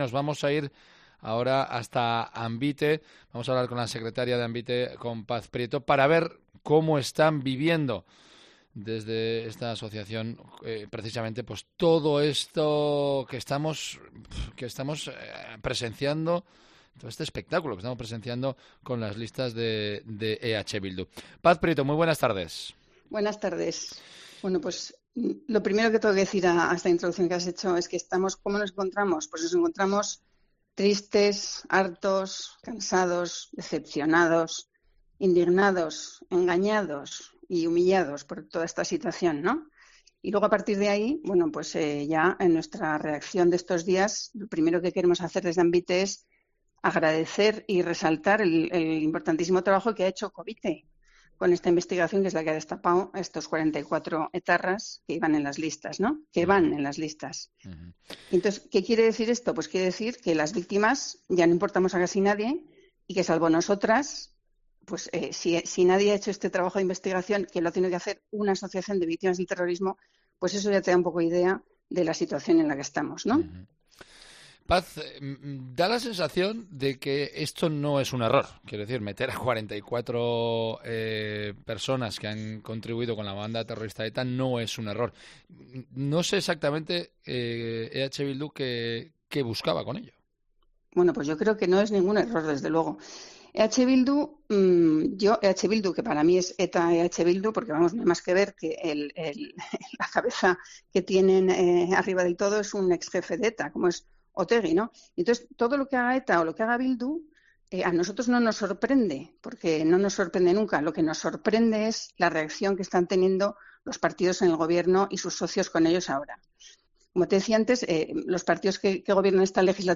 ha pasado por los micrófonos de Cope Navarra.